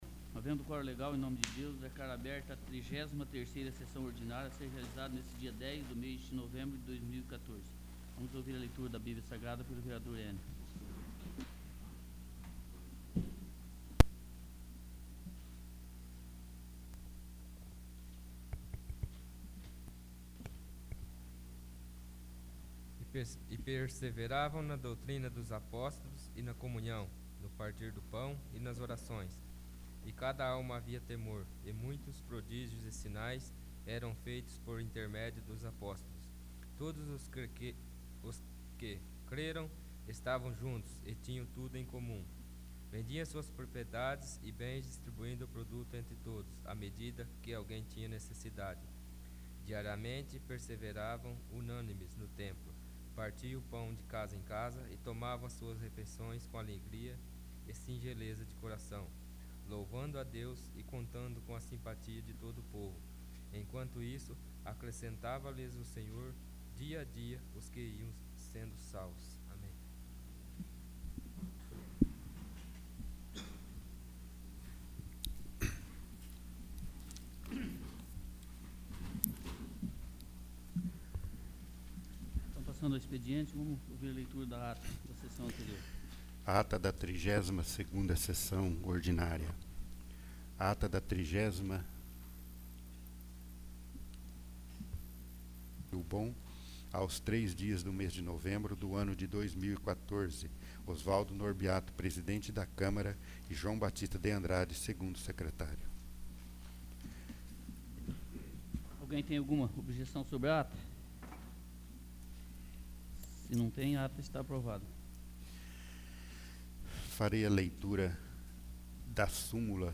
33º. Sessão Ordinária